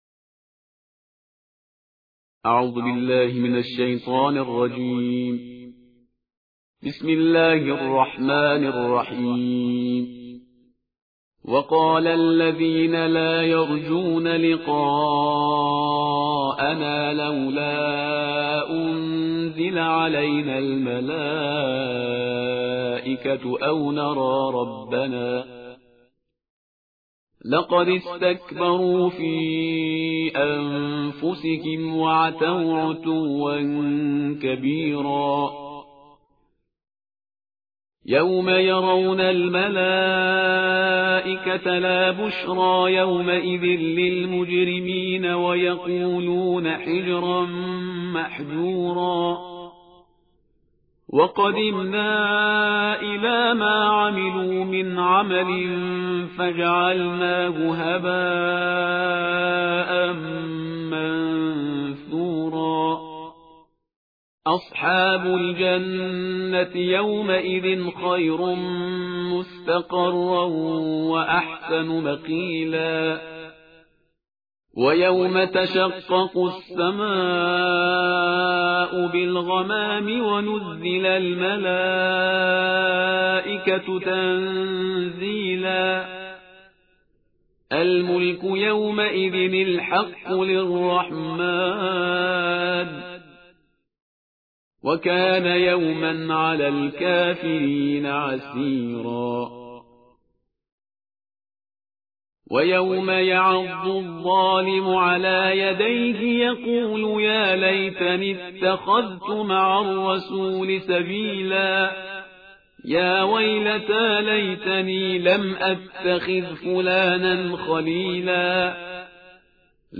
ترتیل جزءنوزده قرآن کریم/استاد پرهیزگار